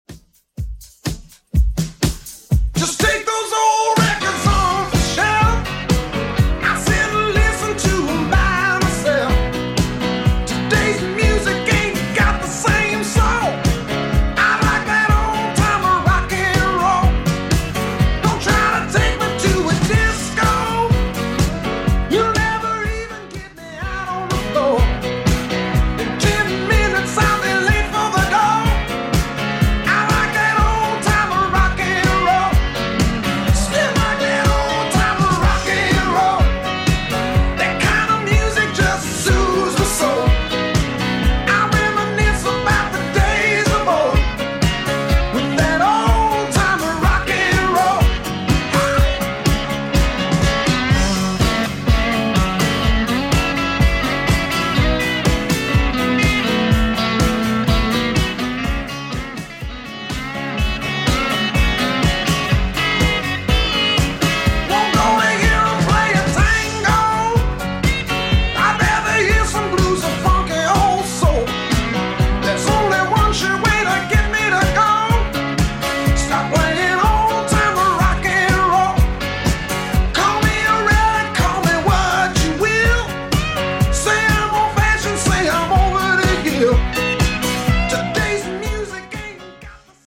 Genre: 70's
BPM: 132